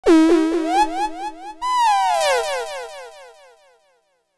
WhisperCat.wav